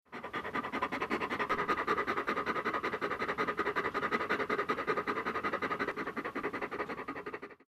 Dog Breathing Sound Button - Free Download & Play